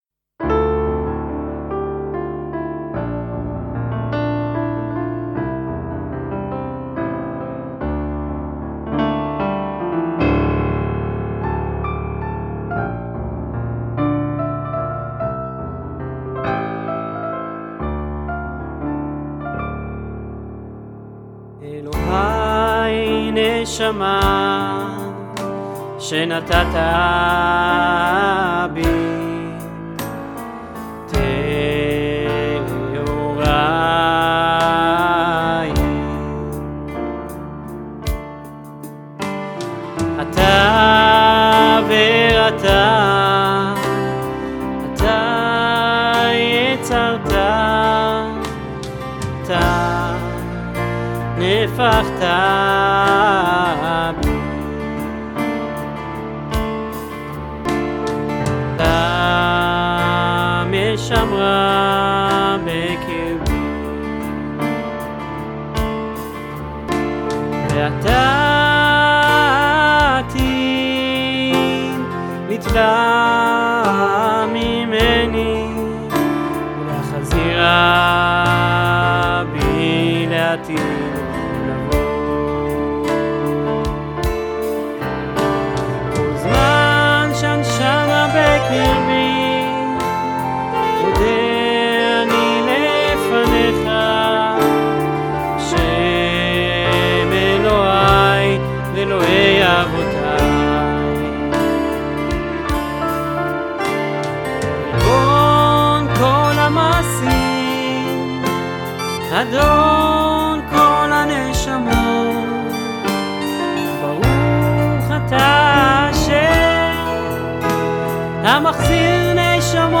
לחן עיבוד ונגינה: אנוכי (הקלטה של כמה ערוצים באורגן)